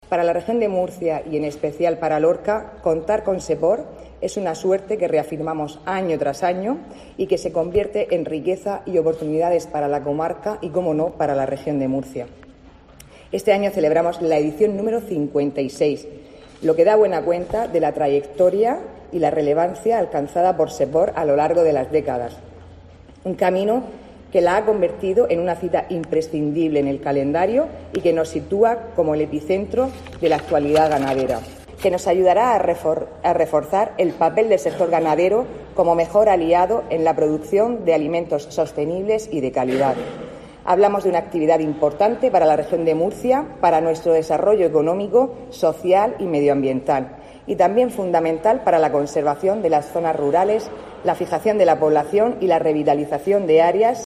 Sara Rubira, consejera de Ganadería